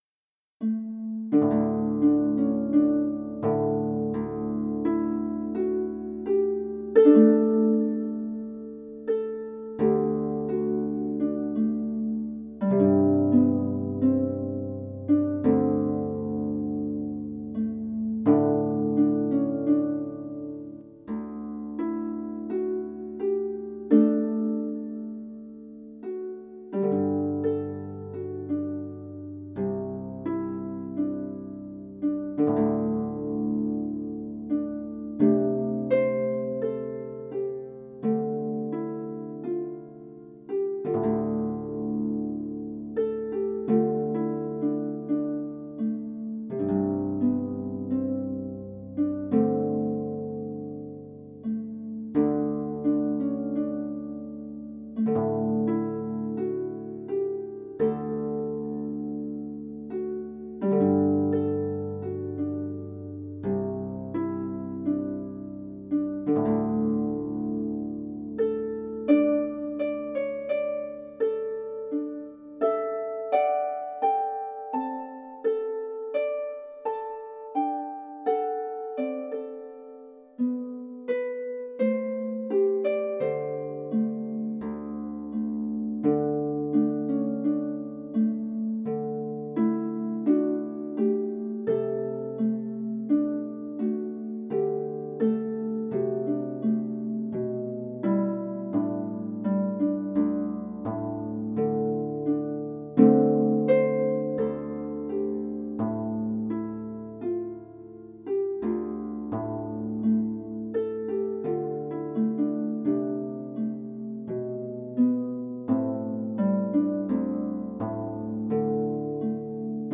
ancient Irish
for solo lever or pedal harp